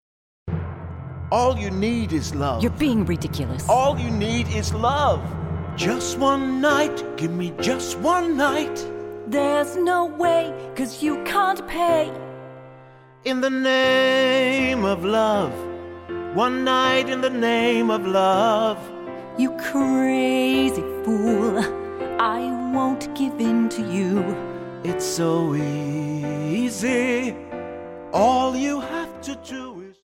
Die besten Playbacks Instrumentals und Karaoke Versionen .